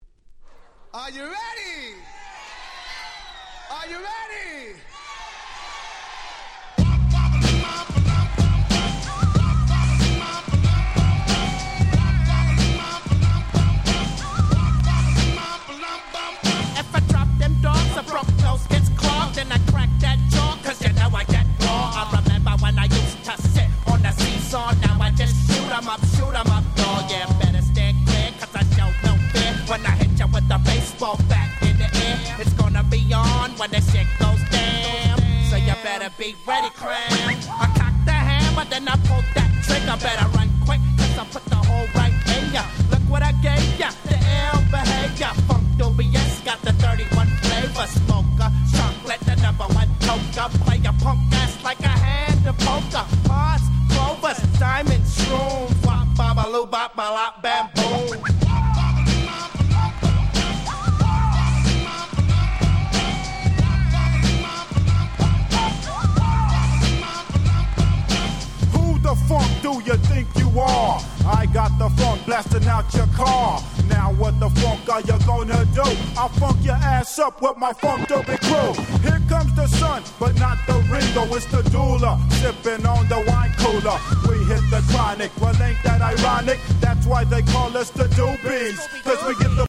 93' Smash Hit Hip Hop !!
相変わらずの独特のFreakyなFlowが冴え渡る格好良いBoom Bapナンバー！！